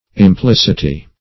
Implicity \Im*plic"i*ty\, n.